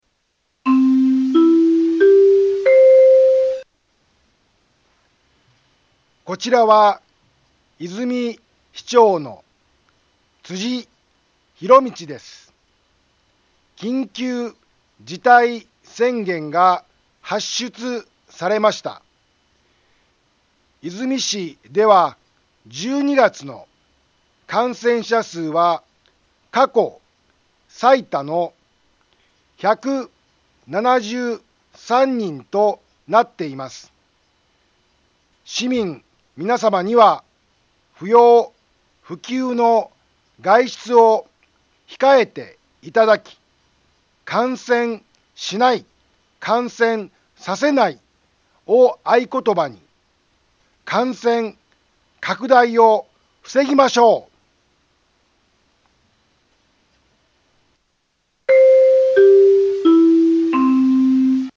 BO-SAI navi Back Home 災害情報 音声放送 再生 災害情報 カテゴリ：通常放送 住所：大阪府和泉市府中町２丁目７−５ インフォメーション：こちらは、和泉市長の辻 ひろみちです。 緊急事態宣言が発出されました。 和泉市では１２月の感染者数は過去最多の１７３人となっています。